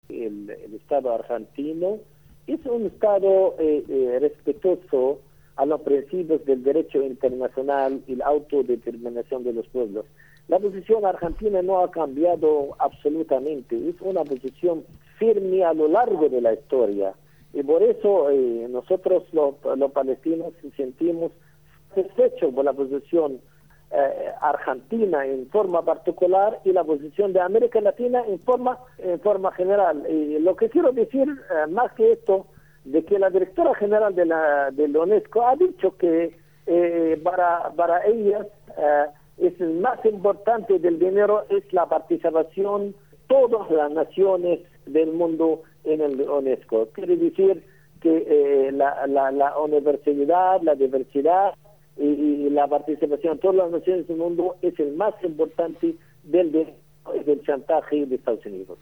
Walid Muaqqat, embajador de Palestina en Argentina